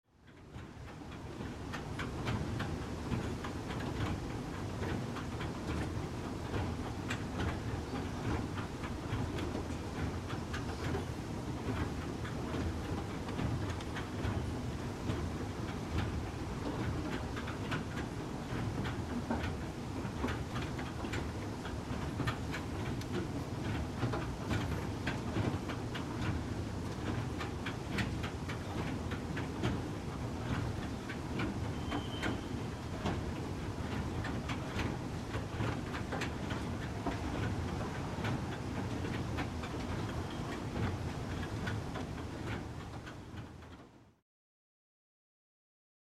На этой странице собраны разнообразные звуки эскалаторов: от плавного гула двигателя до ритмичного стука ступеней.
Шум неторопливого эскалатора